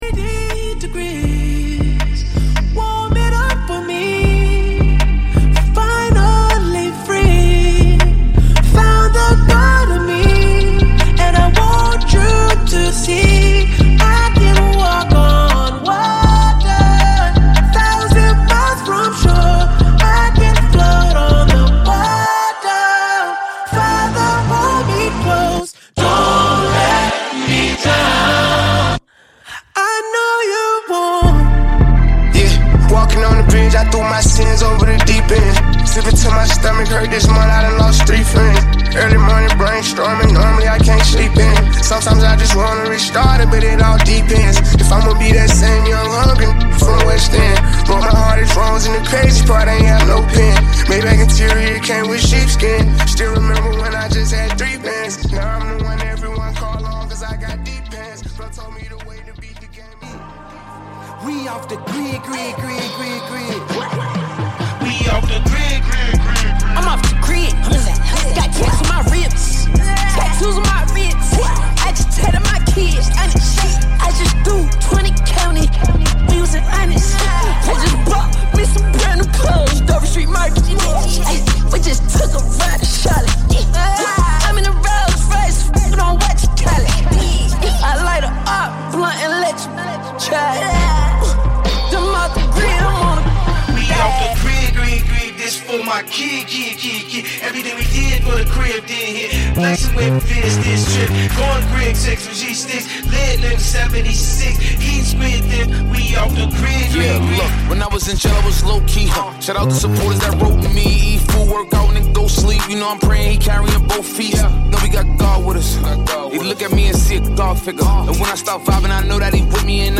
Over 111 MB of Data in this massive Soul Hip-Hop & Trap Sample pack!
SNARES – 70
KICKS – 32
VOCAL SHOTS- 26
HI-HATS – CYMBALS – 43
PERCUSSION- 79
808 BASS – 20